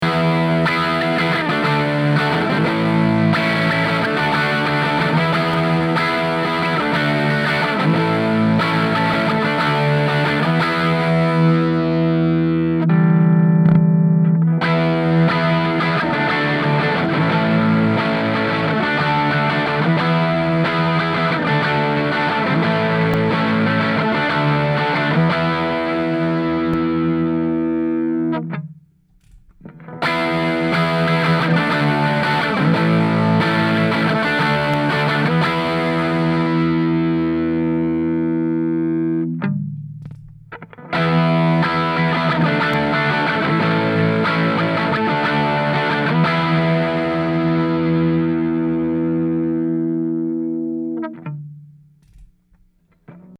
While it acts essentially as a subtle high-cut filter, it’s not an EQ.
Here’s a clip that demonstrates the Variable Dynamic Control:
The fundamental tone doesn’t change much, but going from extreme to extreme, you can tell when the highs roll off a bit.